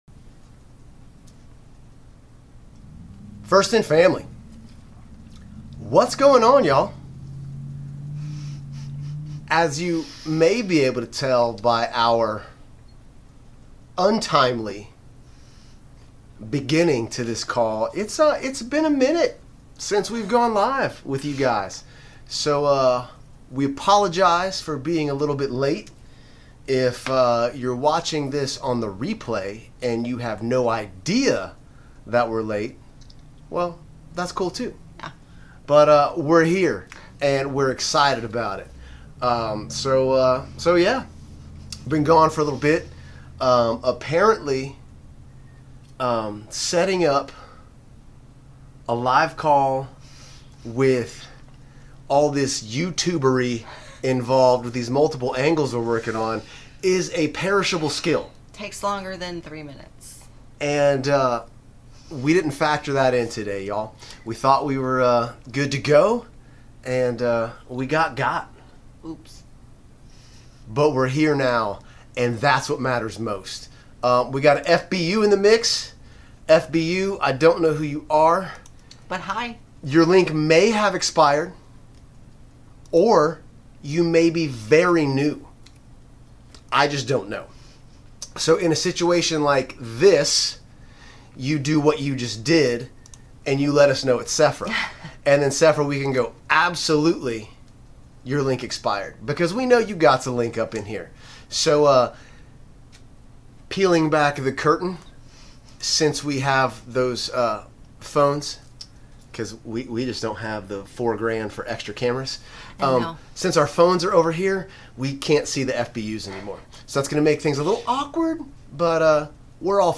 FIN LIVE Q+A: 9/1/23